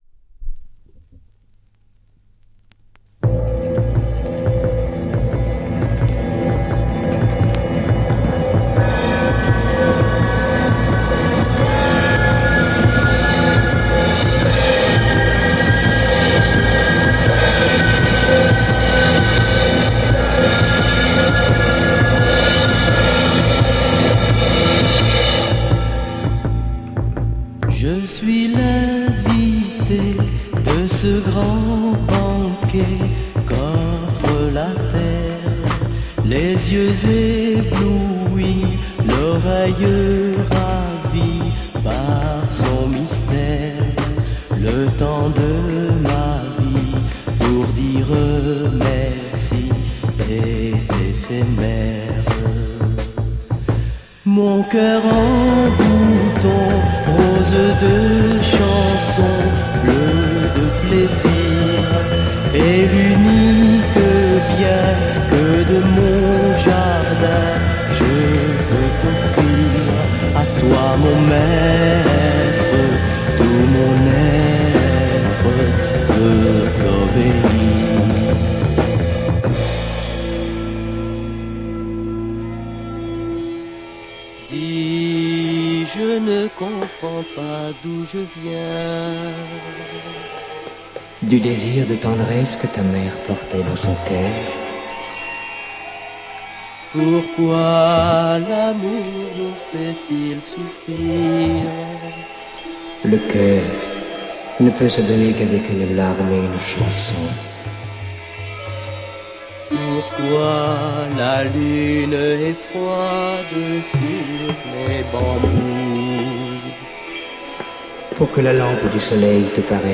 Ultra rare French LSD Psych NM!